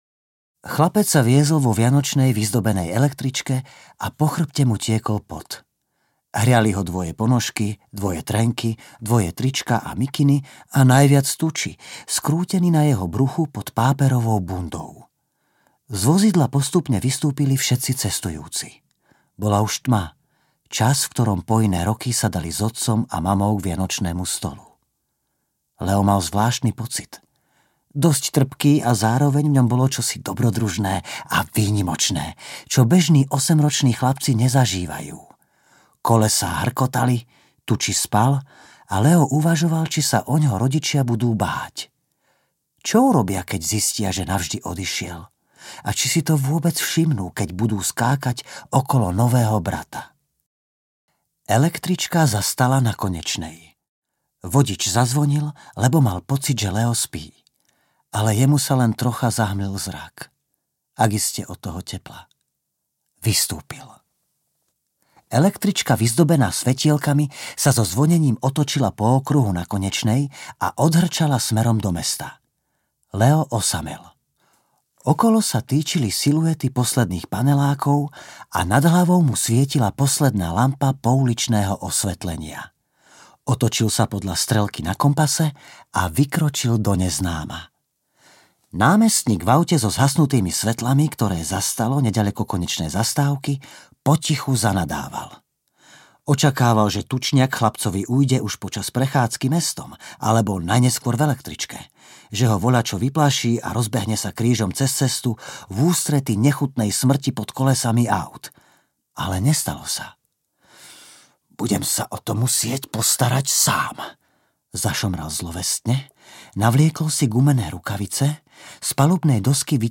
Hon na tučniaka audiokniha
Ukázka z knihy
• InterpretJuraj Hrčka